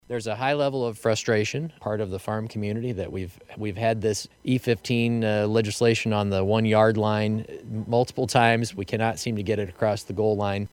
IOWA SECRETARY OF AGRICULTURE MIKE NAIG SAYS HE REMAINS OPTIMISTIC, EVEN THOUGH HE AND FARMERS ARE CONCERNED: